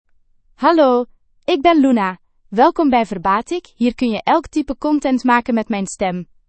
Luna — Female Dutch (Belgium) AI Voice | TTS, Voice Cloning & Video | Verbatik AI
Luna is a female AI voice for Dutch (Belgium).
Voice sample
Listen to Luna's female Dutch voice.
Luna delivers clear pronunciation with authentic Belgium Dutch intonation, making your content sound professionally produced.